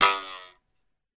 comedy_twang_002